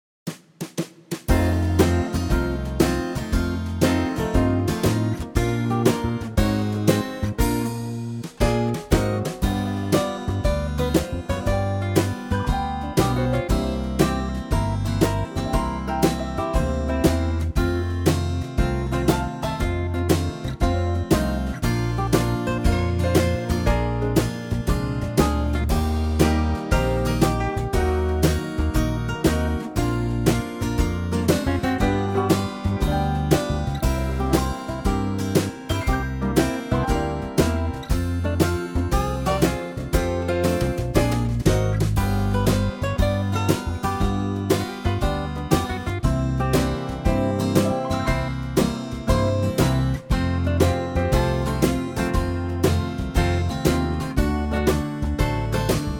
LAPSTEEL GUITAR & SOLO REMOVED!
key - Bb - vocal range - Ab to A
Superb Country arrangement